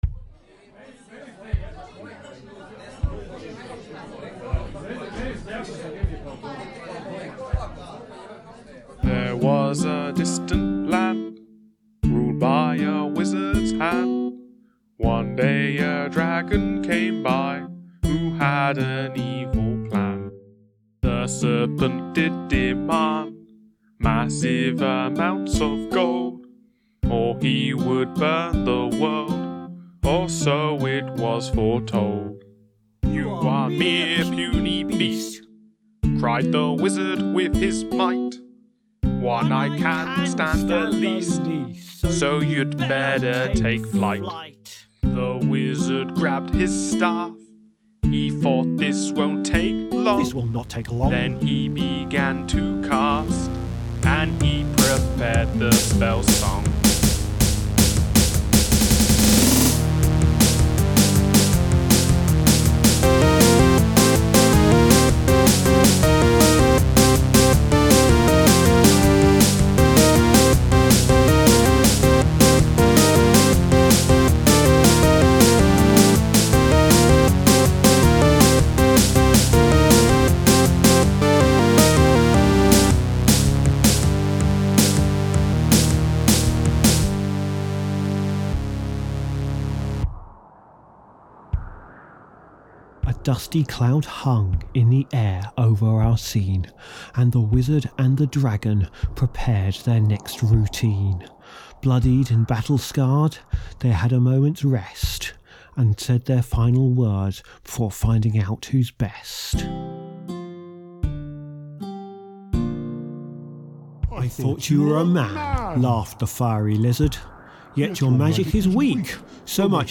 FediVision 2024
This track is an alien folk song about